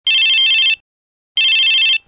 Suoneria telefono cellulare vintage Nokia 2110
Effetto sonoro - Suoneria telefono cellulare vintage Nokia 2110